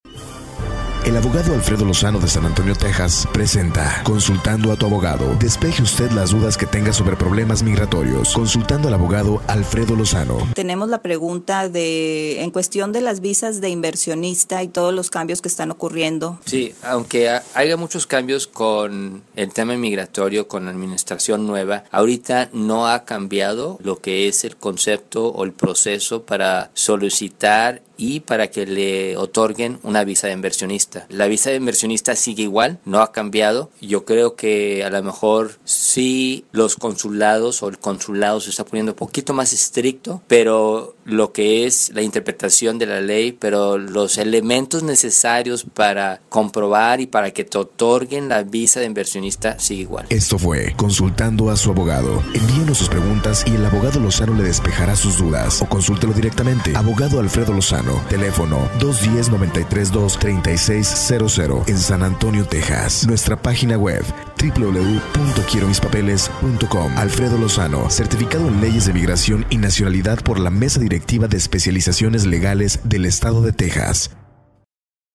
ALL RADIO INTERVIEWS ARE IN SPANISH